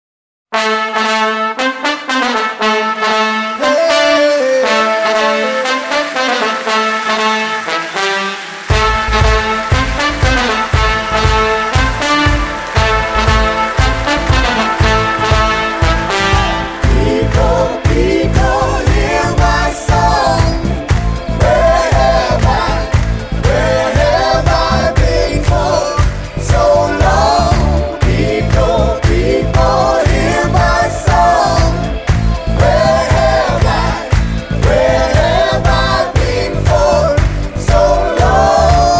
The absolute party song
European Pop
with with a slight African taste